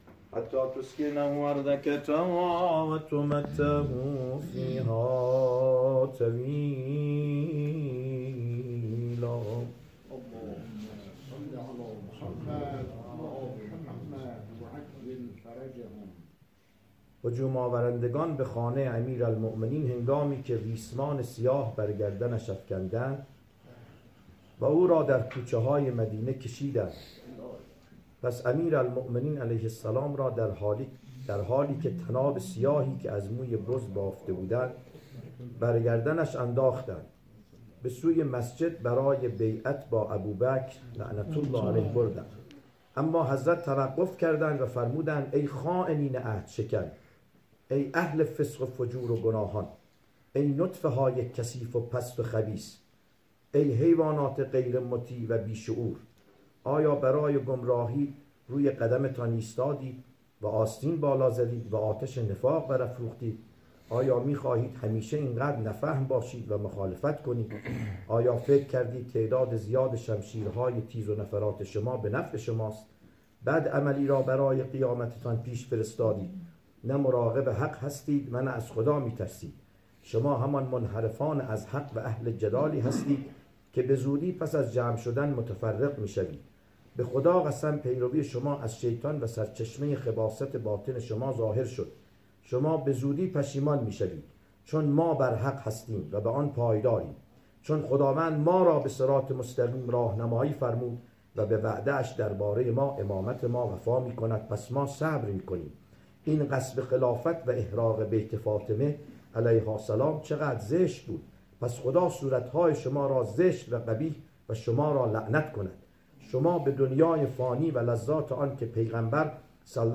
با مداحی